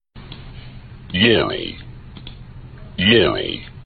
Es geht um das Laurel/Yanny-Phänomen, das in letzter Zeit schon in aller Munde war, eine kurze Tonaufnahme eines Sprechers, der "Laurel" ("lohr'l") sagt.
Die meisten tippen wohl auf ersteres, aber je nach Lautstärke und Lautsprecher kann man wohl beides hören.
Turn the volume up and play it on some speakers that have actual bass response (aka not your phone) and you will hear Laurel.
Im Prinzip sind es, wie oben beschrieben wohl die tieferen Frequenzen von "Laurel", auf die die höheren Frequenzanteile von "Yanny" draufgelegt wurden, und das Ganze mischt sich so gut, weil der Schall der verschiedenen, überlagerten Sprechlaute der beiden Wörter wohl jeweils ähnlich viel Energie hat.